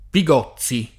[ pi g0ZZ i ]